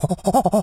monkey_chatter_07.wav